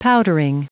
Prononciation du mot : powdering
powdering.wav